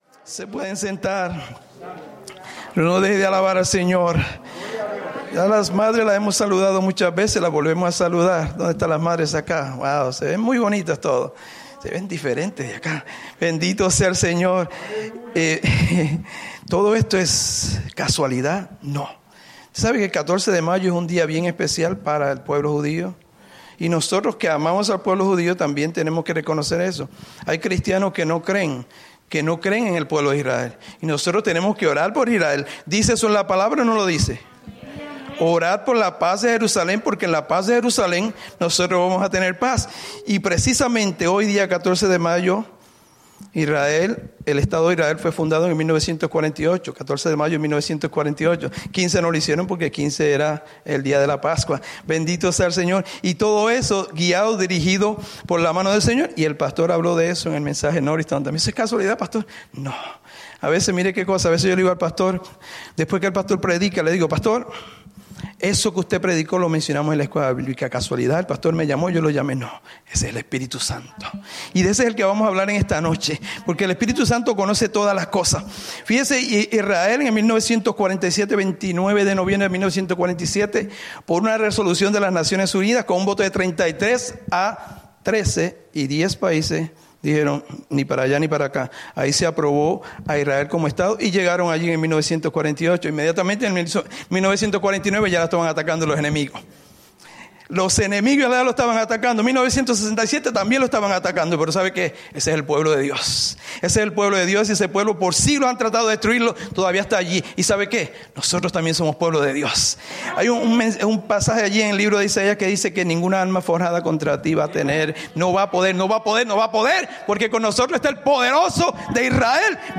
Predica
Souderton, PA